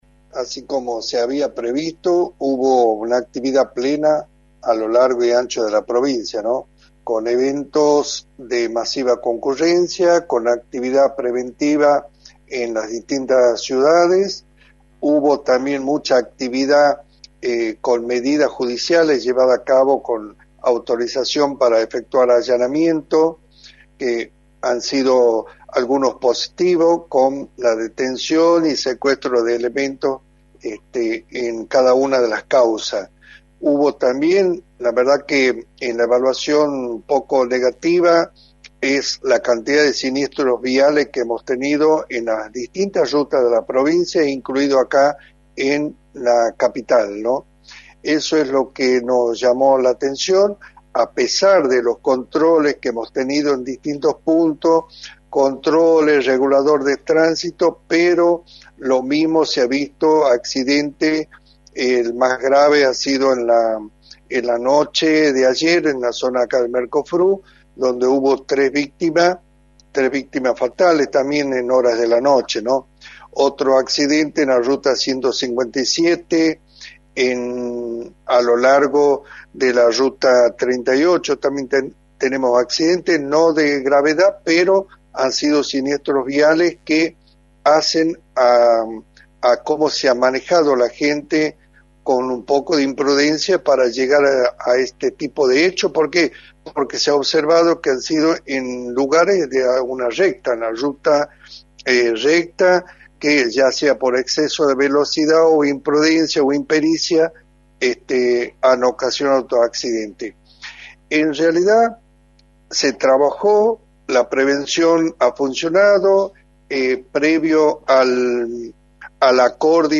Luís Ibáñez, Secretario de Seguridad de la provincia, remarcó en Radio del Plata Tucumán, por la 93.9, las repercusiones de los operativos realizados en la provincia durante el fin de semana largo.
“Hubo una actividad plena a lo largo y ancho de la provincia con eventos de masiva concurrencia, con actividad preventiva en las distintas ciudades, hubo también mucha actividad con medidas judiciales llevadas a cabo con autorización para efectuar allanamiento, que han sido algunos positivos, con la detención y secuestro de elementos en cada una de las causas, hubo también, la verdad que en la evaluación un poco negativa, es la cantidad de siniestros viales que hemos tenido en las distintas rutas de la provincia incluido acá en la capital” señaló Luís Ibáñez en entrevista para “La Mañana del Plata”, por la 93.9.